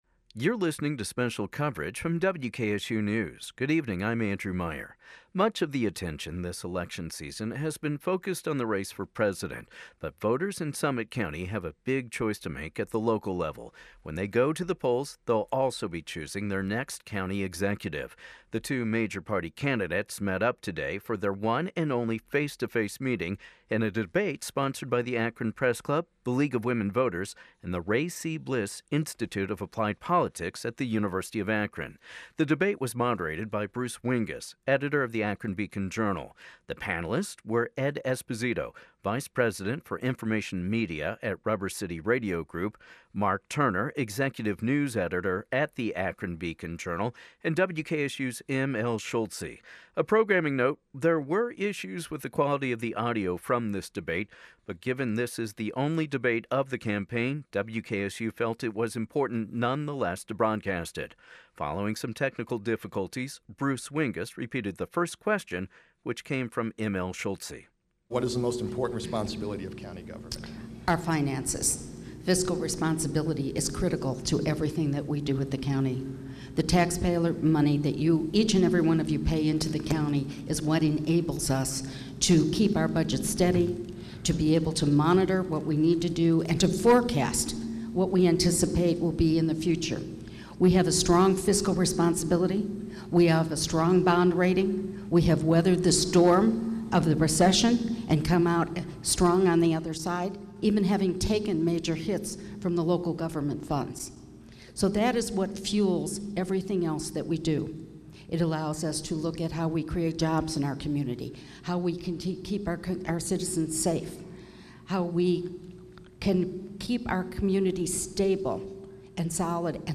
Republican Bill Roemer and Democrat Ilene Shapiro, the candidates for Summit County executive, held their first and only debate today at Quaker Station in Akron.
About 300 people gathered in the ballroom to hear the candidates' positions.
2016summitdebate.mp3